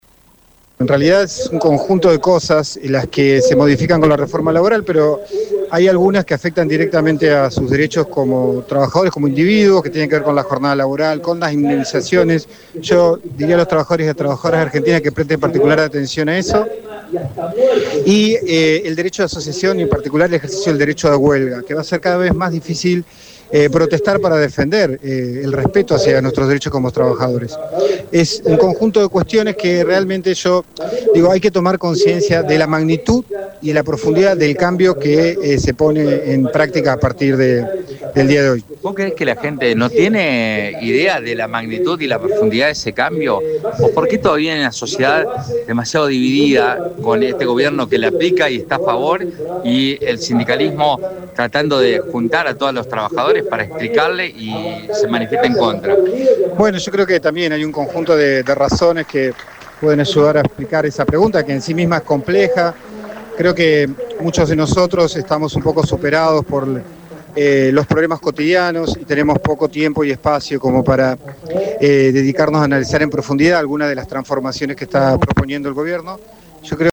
En el marco de una jornada de protesta nacional, este jueves se llevó a cabo una clase pública sobre la reforma laboral en pleno centro de la ciudad de Santa Fe.